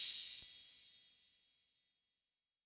Open Hats